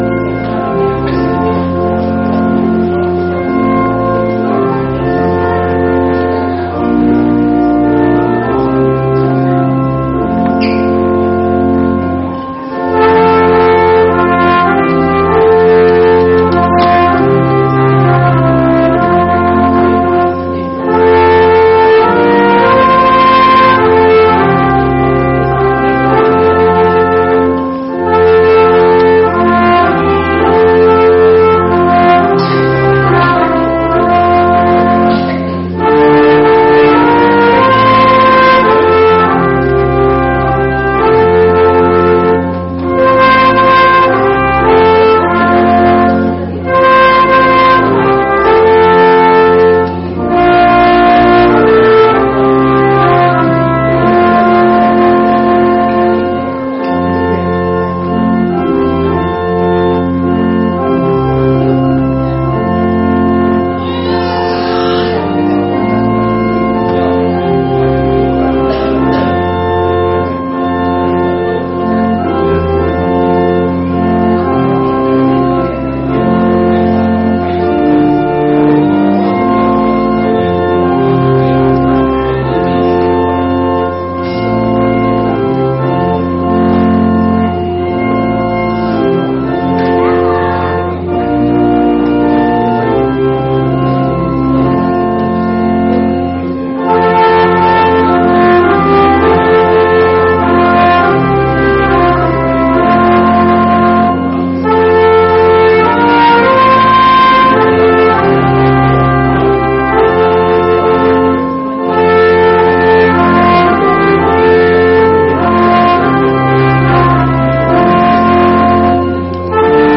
Gottesdienst Sonntag 14.12.2025 | Evangelisch-altreformierte Kirchengemeinde Laar
Wir laden ein, folgende Lieder aus dem Evangelischen Gesangbuch mitzusingen: Lied 177, 2, Lied 7, 1-5, Lied 8, 1-4, Lied 15, 1-6, Lied 10, 1-4, Lied 1, 1+4+5
Gottesdienst.mp3